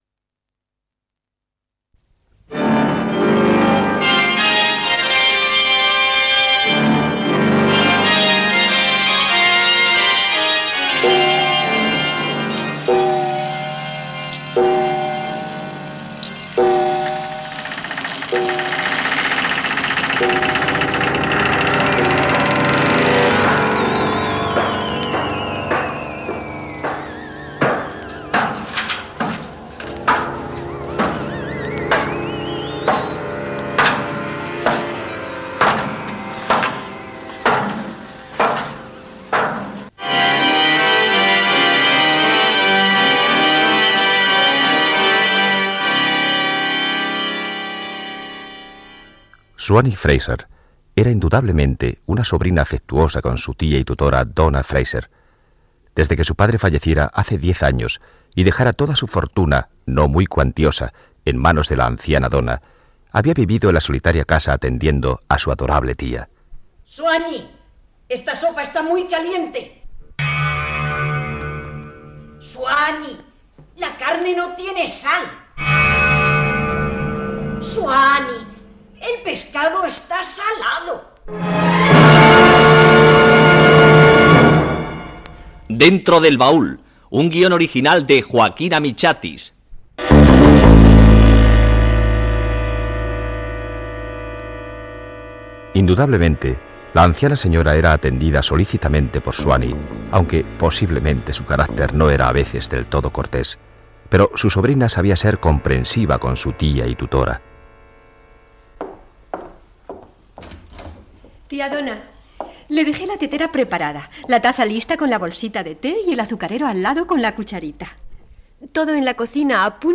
Introducció, careta del programa, ficció sonora i crèdits Gènere radiofònic Ficció